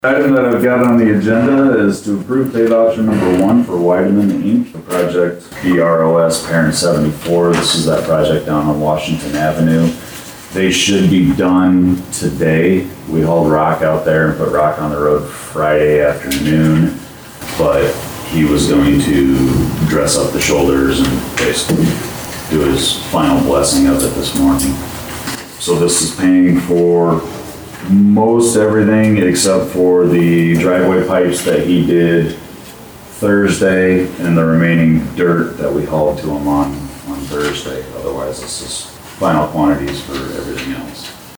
Dakota City, IA – At Monday’s Humboldt Board of Supervisors Meeting, A pay voucher was approved for a project in Humboldt County. County Engineer Ben Loots has the details on the project.